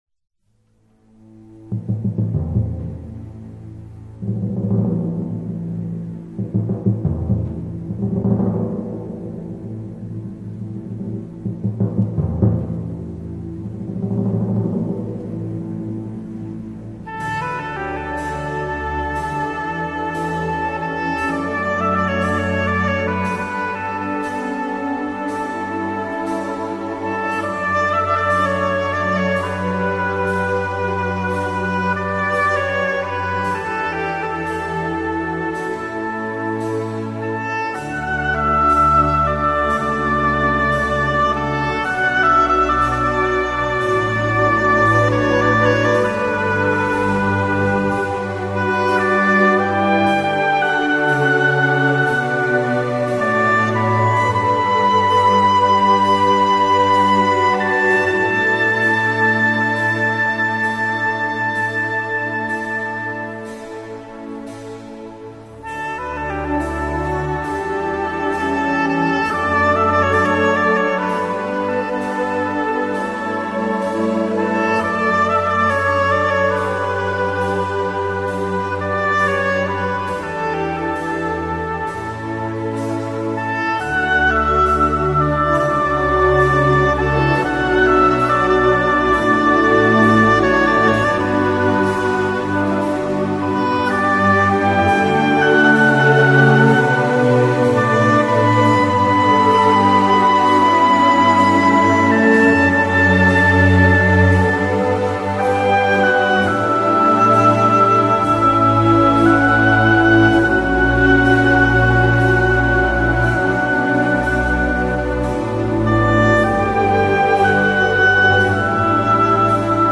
- hautbois :